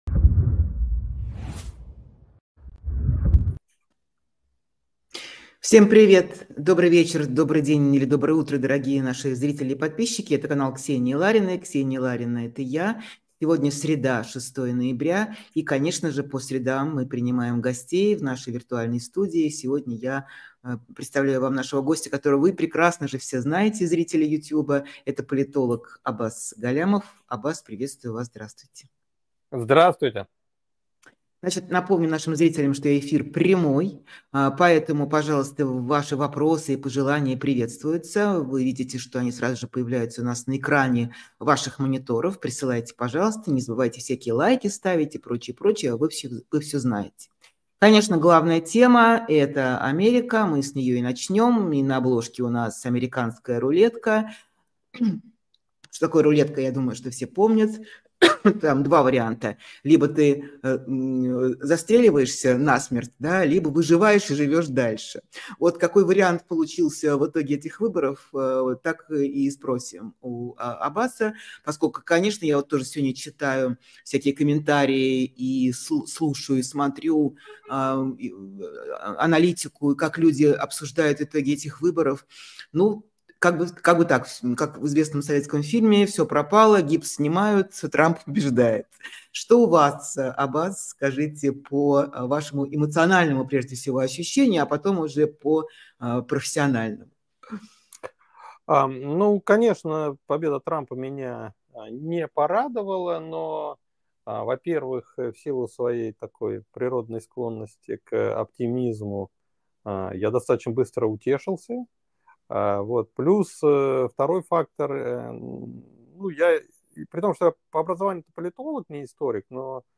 Эфир Ксении Лариной